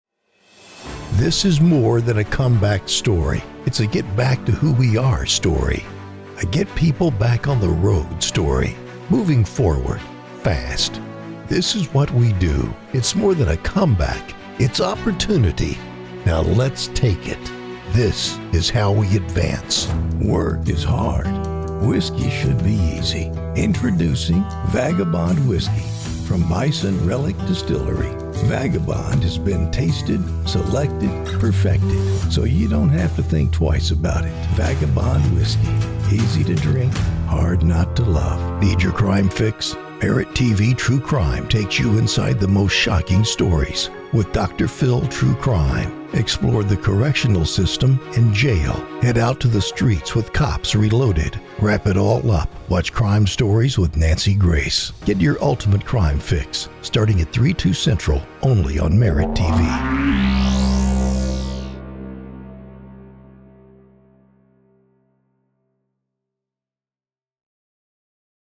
Winter 2025 Demo Reel
Mid-South meets Mid-West
Middle Aged
Senior
Commercial